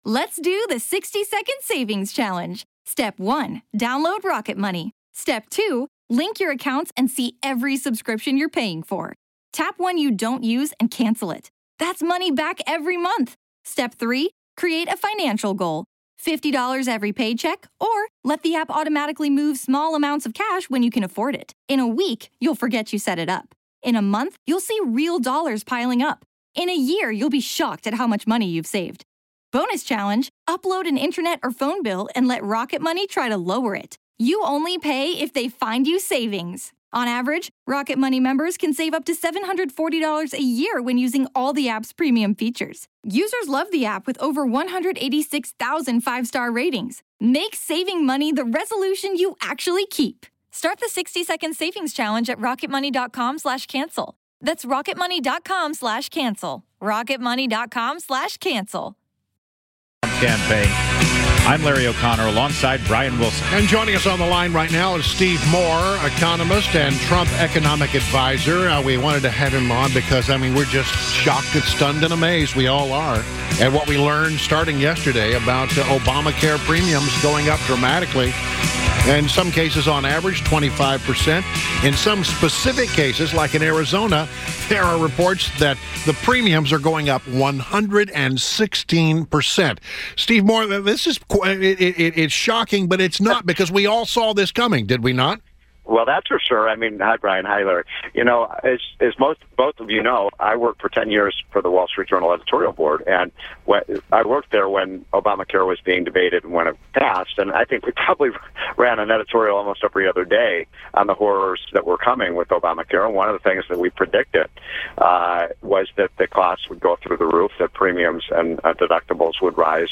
INTERVIEW — STEVE MOORE — Economist and a Trump economic adviser